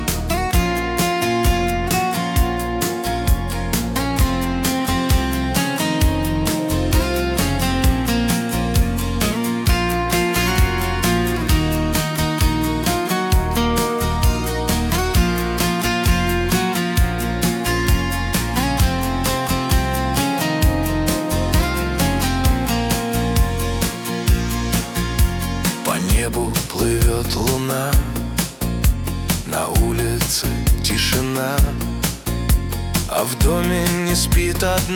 Chanson
Жанр: Русский поп / Русский рэп / Русский шансон / Русские